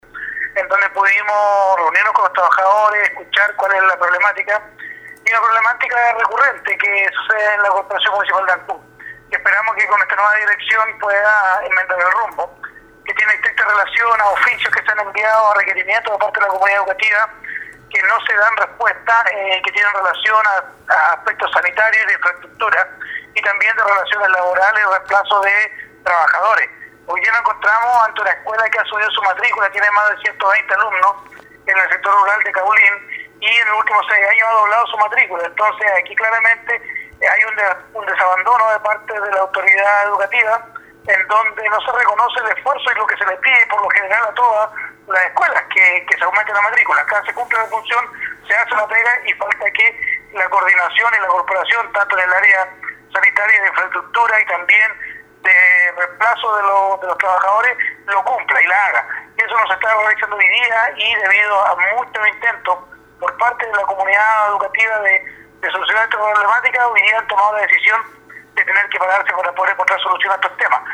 Con ocasión de esta toma del recinto, a primera hora se reunió el concejal Andrés Ibáñez con los estamentos movilizados, donde plantearon éstas y otras demandas.
19-CONCEJAL-ANDRES-IBANEZ.mp3